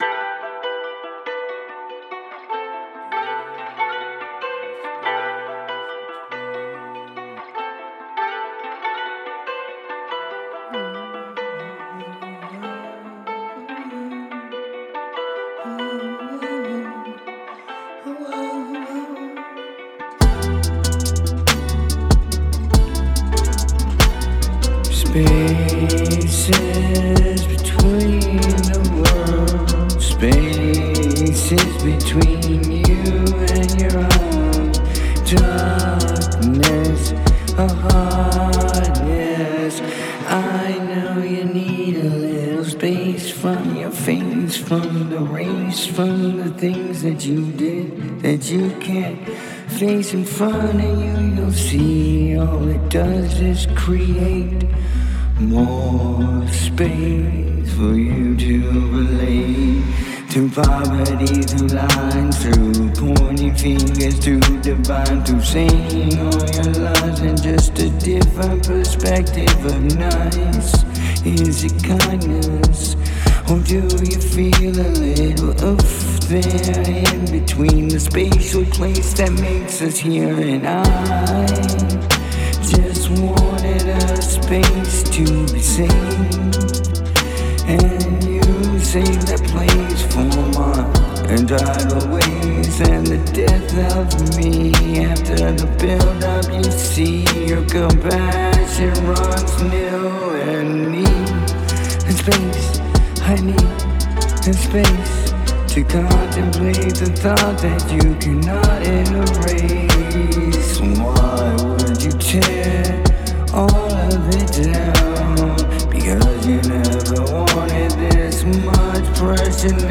Date: 2026-07-12 · Mood: dark · Tempo: 95 BPM · Key: C major
A dark 95 BPM piece in C major.